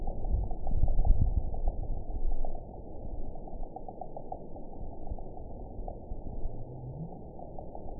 event 922585 date 02/05/25 time 07:03:18 GMT (2 months, 3 weeks ago) score 8.85 location TSS-AB03 detected by nrw target species NRW annotations +NRW Spectrogram: Frequency (kHz) vs. Time (s) audio not available .wav